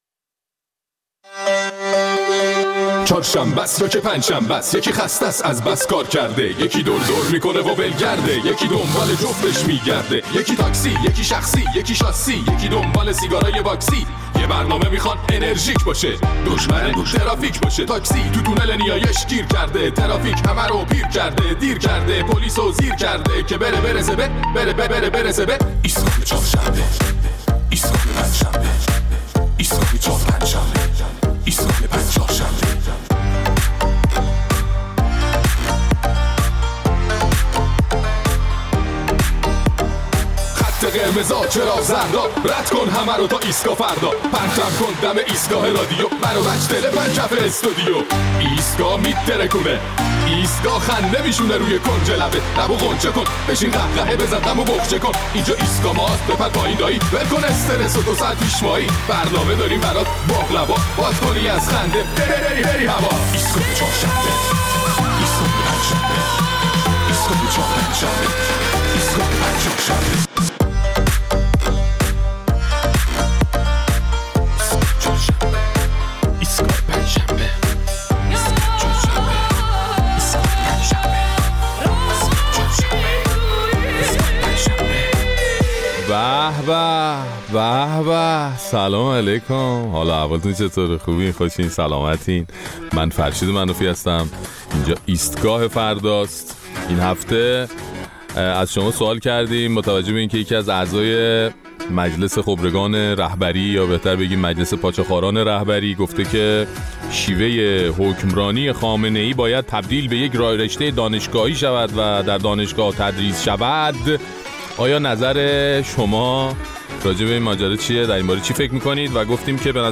در این برنامه ادامه نظرات شنوندگان ایستگاه فردا را در مورد صحبت‌های یکی از اعضای خبرگان که خواستار تدریس شیوه حکمرانی رهبر نظام شده بود می‌شنویم.